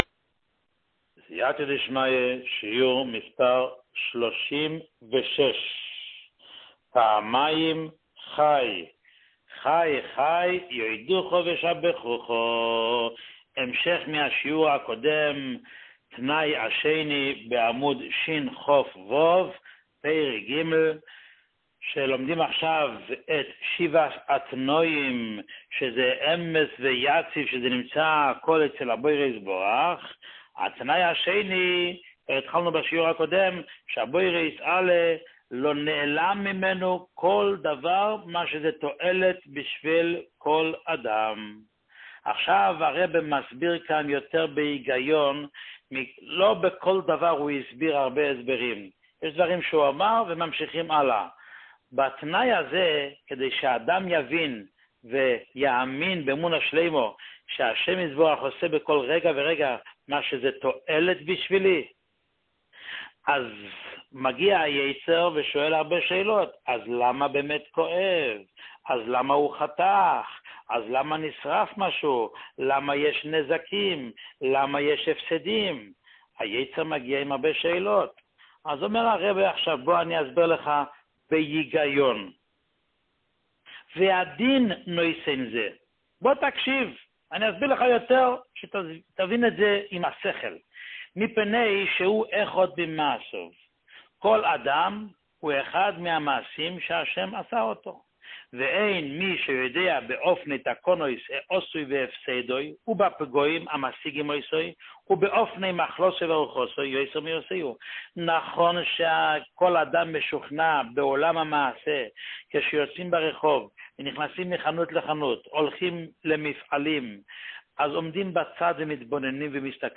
שיעור 36